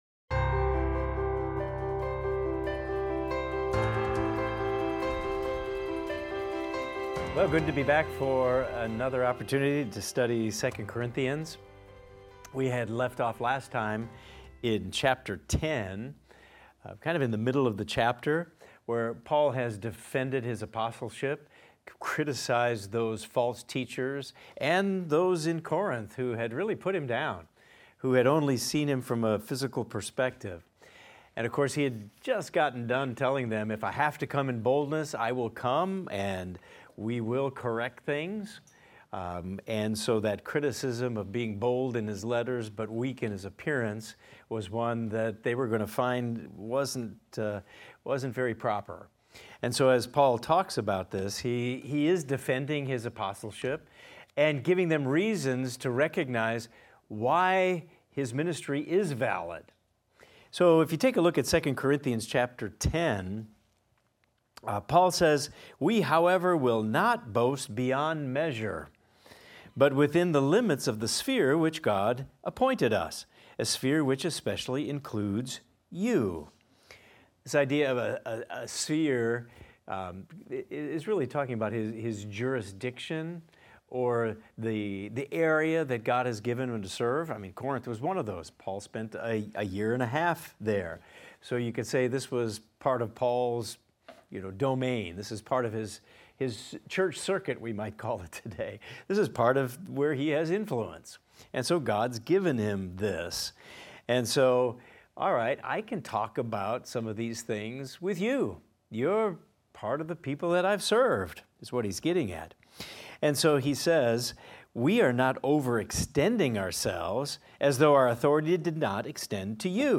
In this class we will discuss 2 Corinthians 10:13 thru 2 Corinthians 11:33 and examine the following: Paul emphasizes that his boasting is limited to the work God has assigned him, aiming to expand the Gospel's reach. He defends his ministry against false apostles, highlighting his genuine apostolic credentials through his sufferings, labors, and perseverance for Christ.